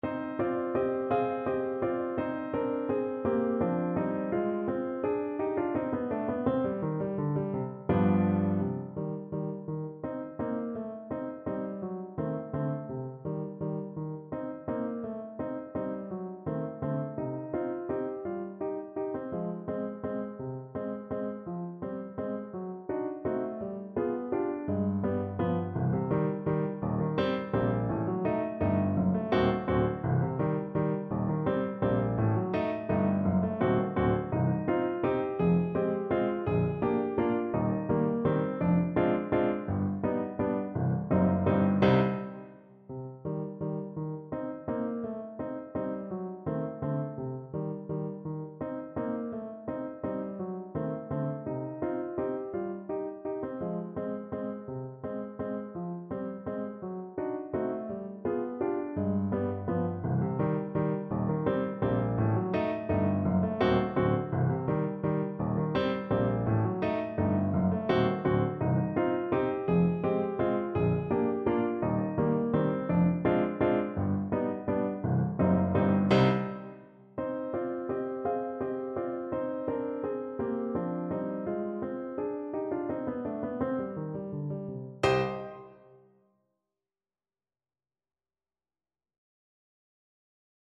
Alto Saxophone
3/4 (View more 3/4 Music)
World (View more World Saxophone Music)
Klezmer Tunes for Saxophone